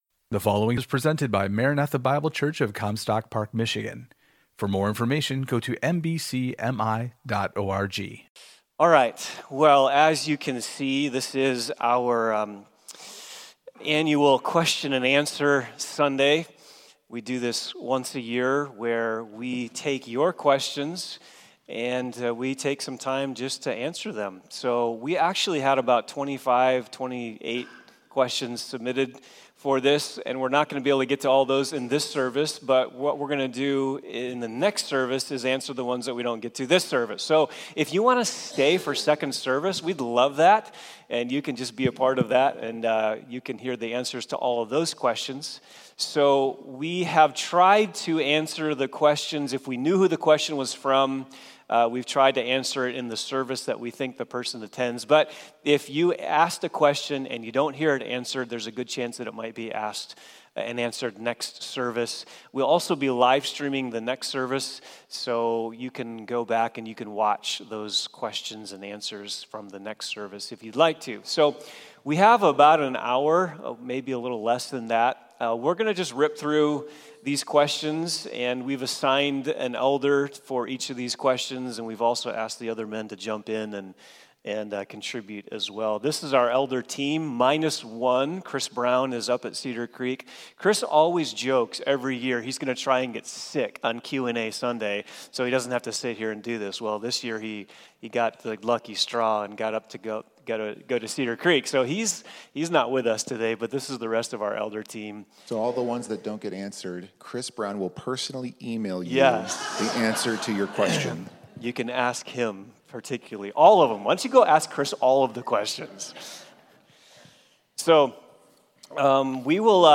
Elder Q & A – Session 1
Question and Answer